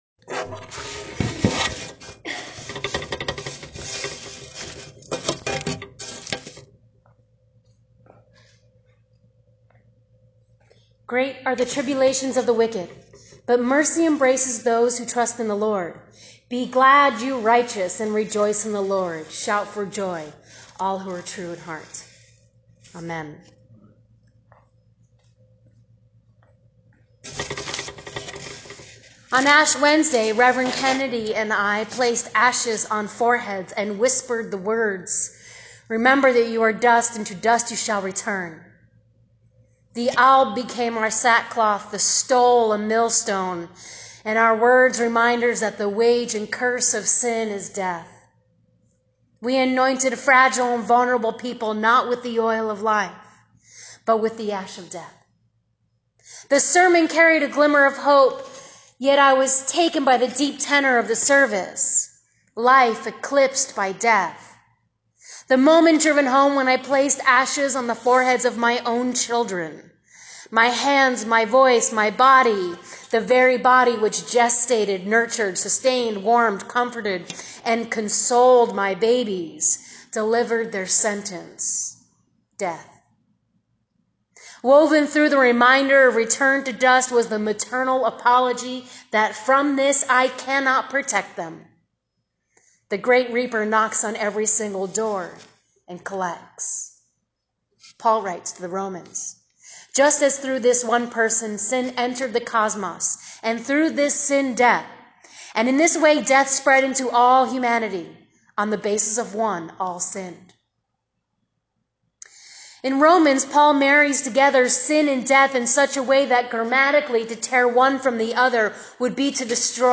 sermon-rom-5.12-19.m4a